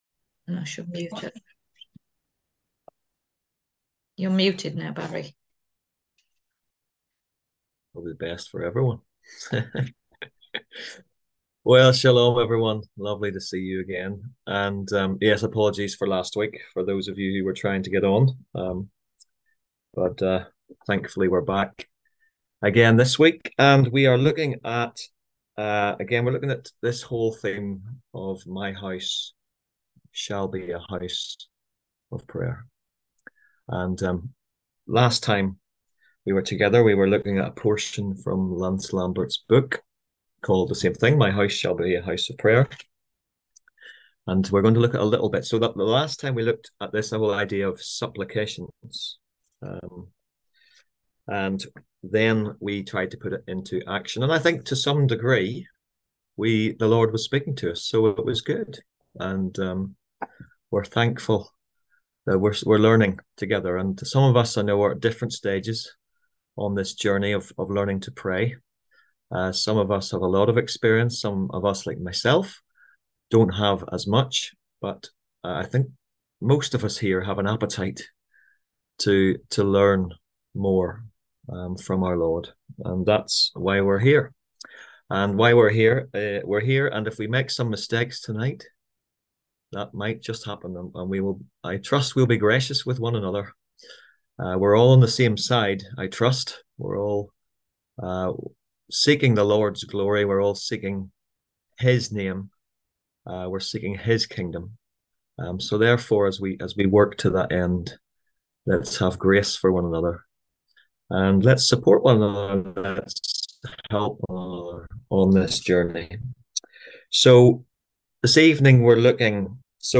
On August 28th at 7pm – 8:30pm on ZOOM ASK A QUESTION – Our lively discussion forum.
On August 28th at 7pm – 8:30pm on ZOOM